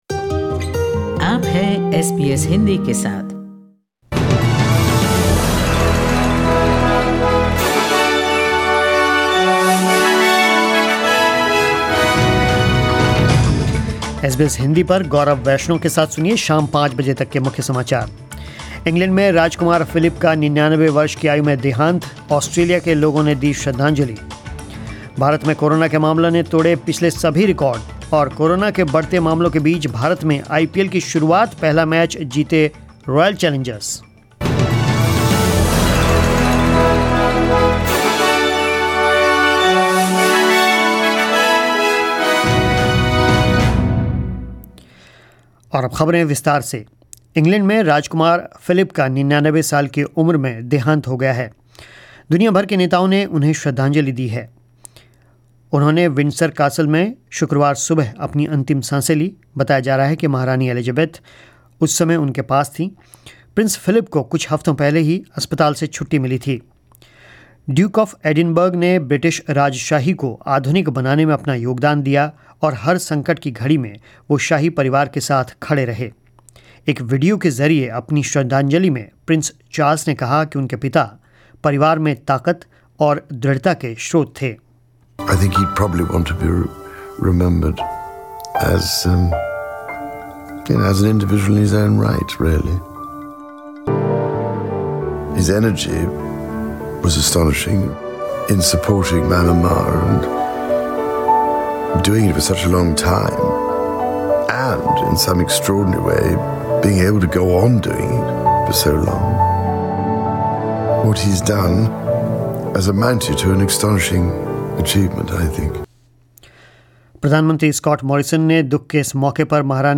News in Hindi: India witnessed an unprecedented surge of 1,45,384 coronavirus cases in one day